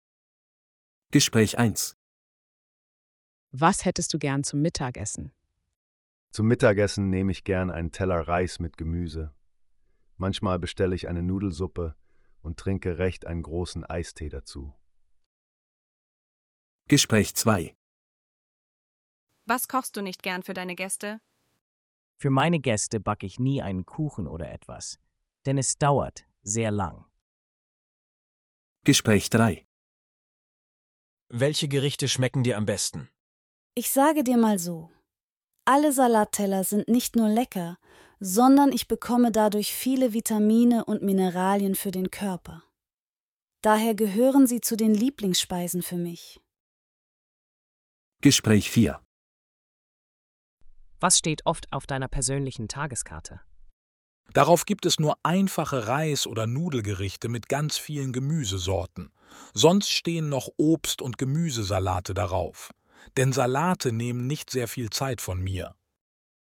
Bài nghe cho các cuộc hội thoại trong bài tập số 4 này: